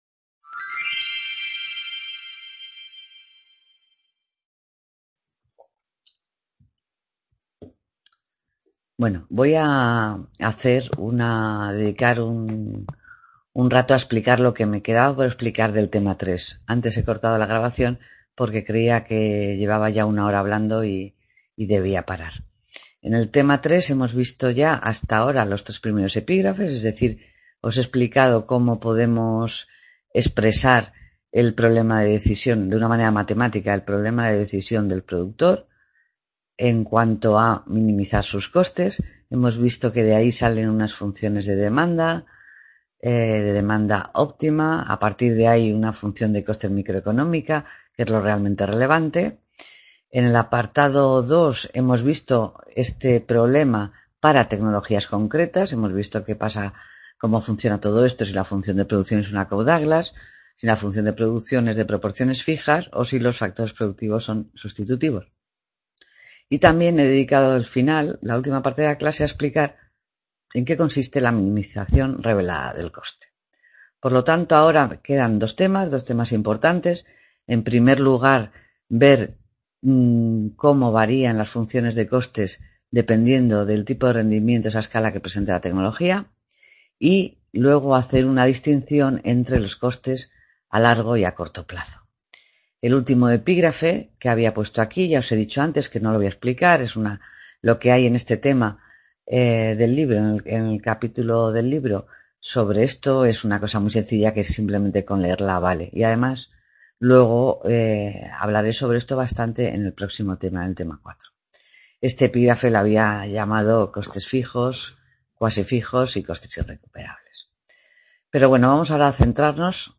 Clase Tema 3: La minimización de los costes (Parte 2/2) | Repositorio Digital